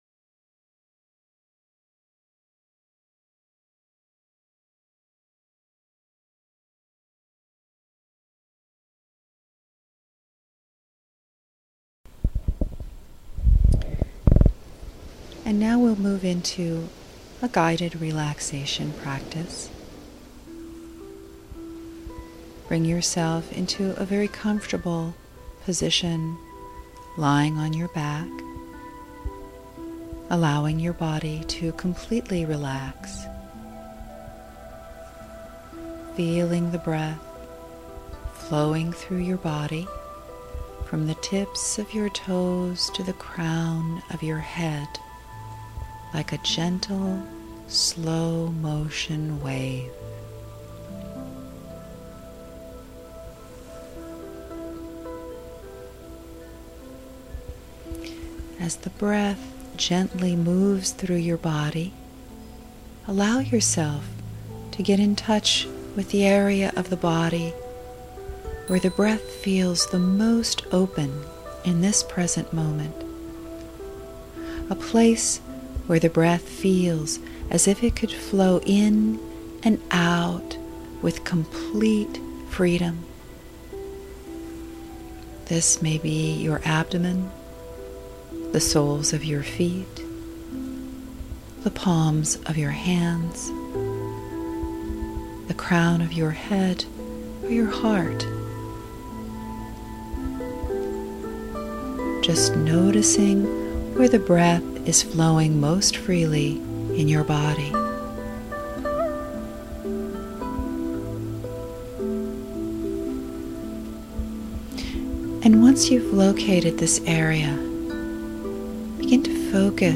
Guided-Relaxation.mp3